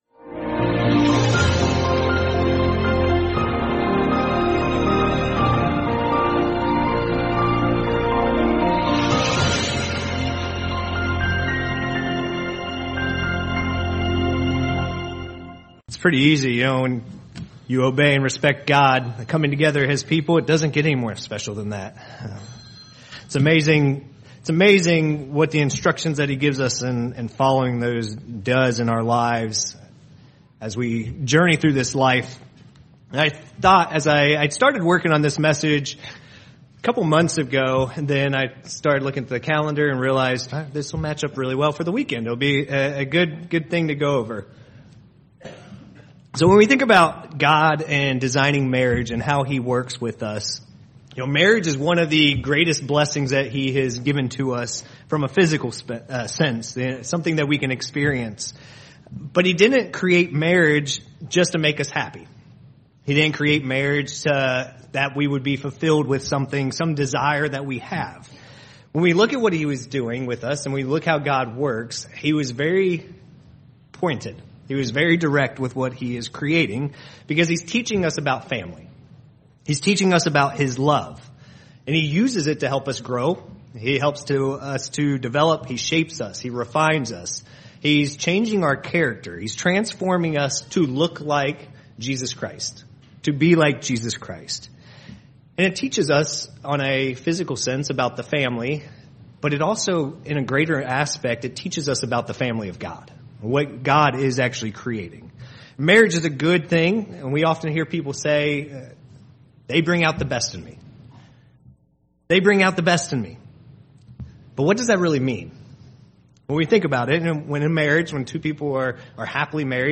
Given in Indianapolis, IN